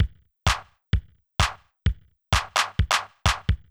CLF Beat - Mix 6.wav